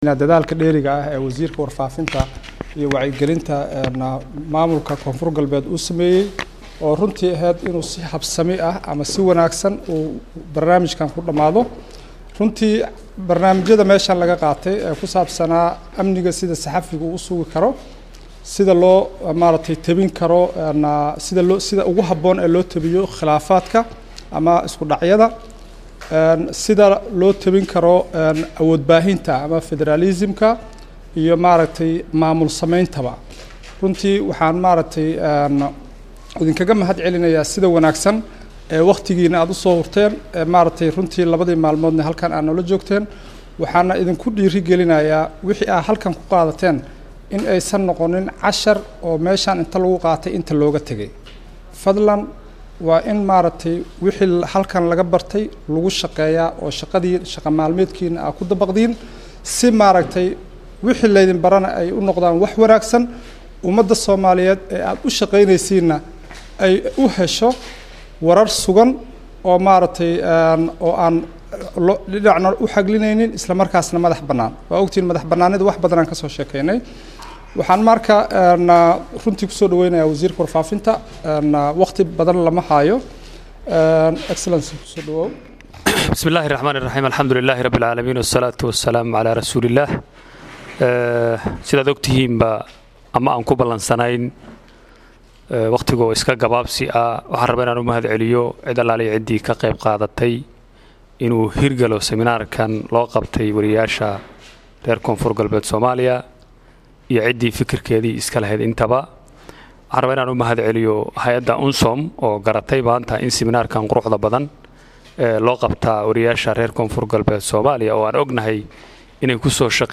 DHAGEYSO: Hadalladii laga jeediyay Xafladii Xirtaankii Suxufiyiinta ee Baydhabo
DHAGEYSO_Xiritaankii_Tababarka_Baydhabo.mp3